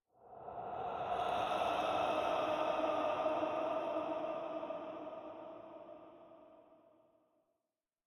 Minecraft Version Minecraft Version snapshot Latest Release | Latest Snapshot snapshot / assets / minecraft / sounds / ambient / nether / soulsand_valley / voices1.ogg Compare With Compare With Latest Release | Latest Snapshot
voices1.ogg